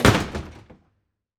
Index of /90_sSampleCDs/AKAI S6000 CD-ROM - Volume 6/Daily_Life/Doors_2
DOOR      -S.WAV